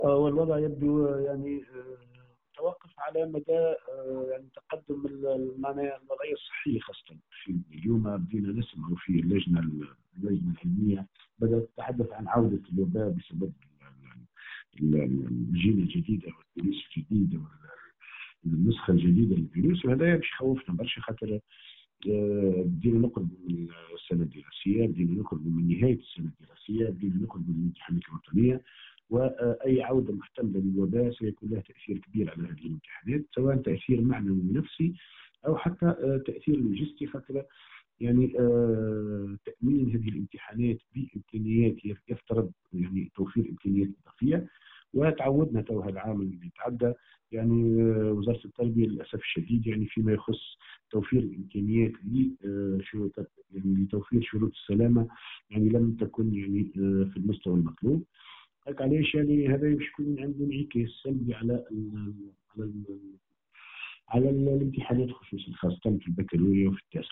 وأضاف في تصريح لتونس الرقمية اليوم الأحد، أن الجامعة متخوفة من السلالة الجديدة لكورونا وما أشارت إليه اللجنة العلمية من عودة انتشار الفيروس،  خاصة وأن الامتحانات الوطنية على الأبواب.